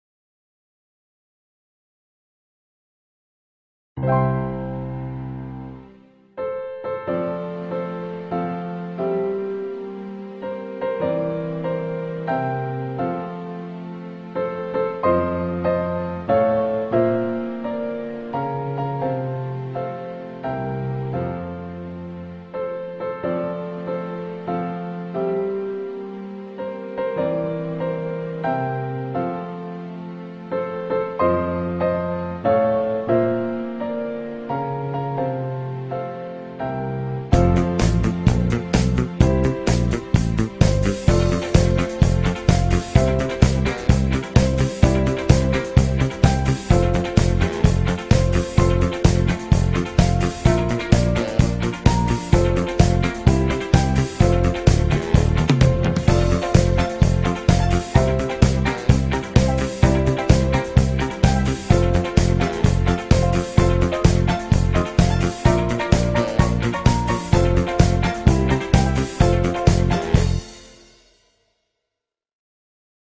شاد بی کلام